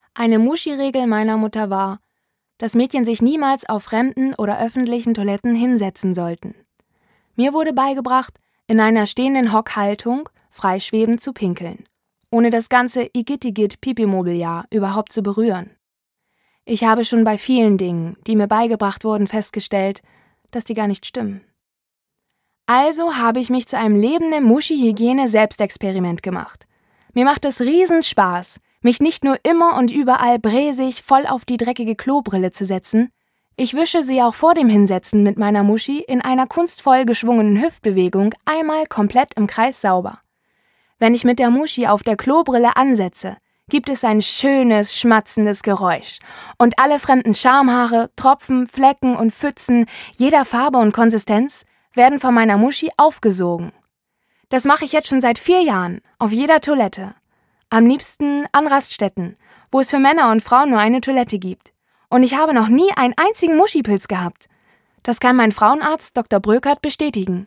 Sprecherin deutsch
norddeutsch
Sprechprobe: Werbung (Muttersprache):
german female voice over artist